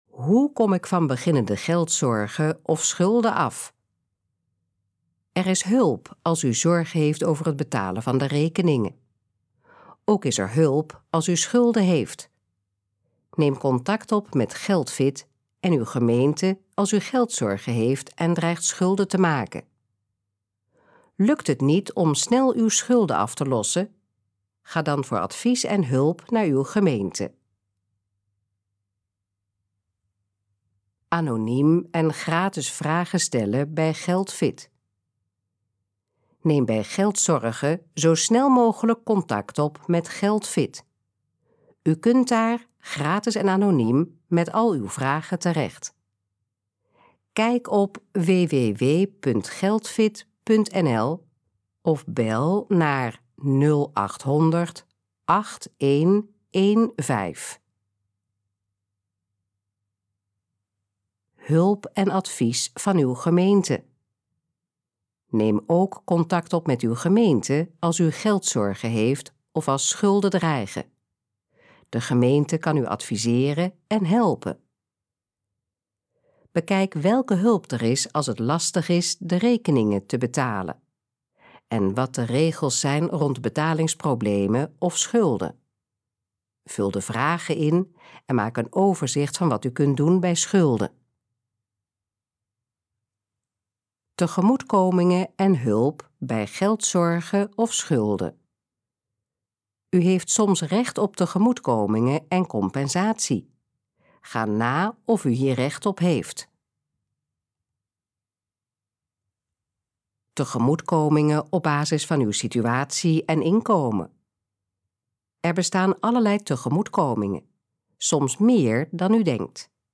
Dit geluidsfragment is de gesproken versie van de pagina: Hoe kom ik van beginnende geldzorgen of schulden af?